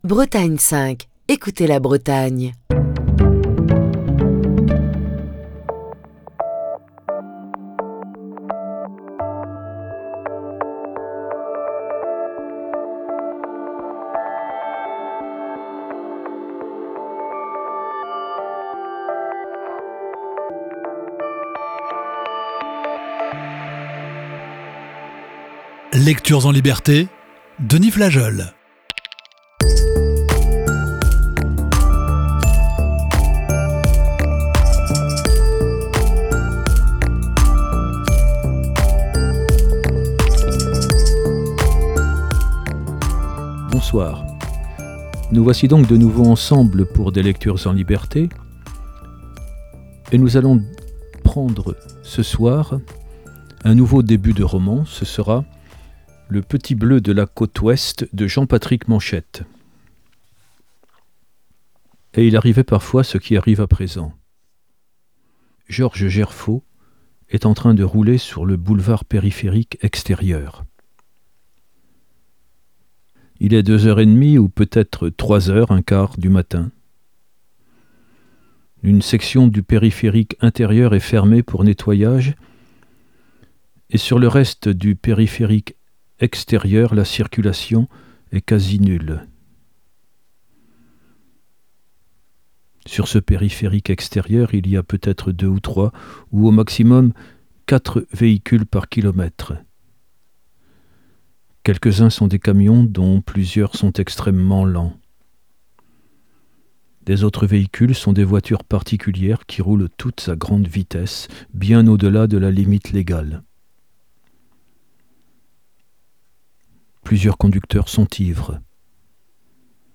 Ce jeudi, voici quelques pages du roman de Jean-Patrick Manchette, Le Petit Bleu de la côte ouest.